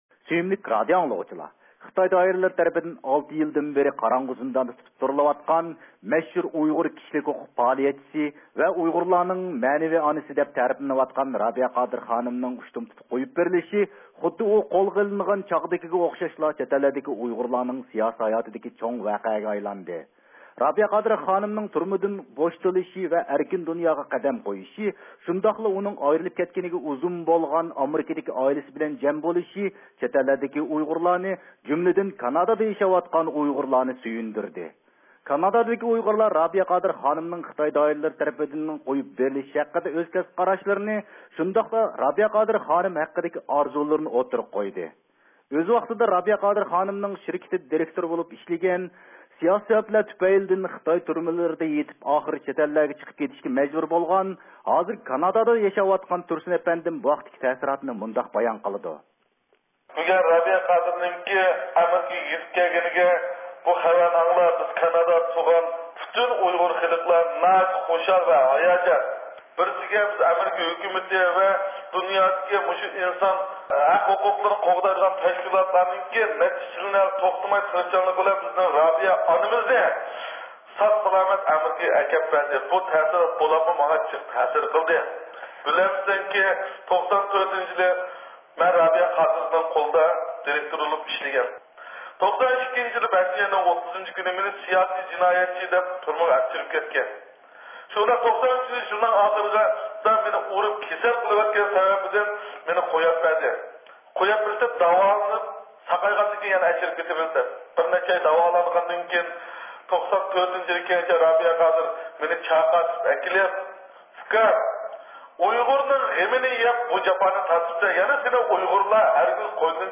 سۆھبەت ئۆتكۈزدى.